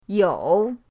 you3.mp3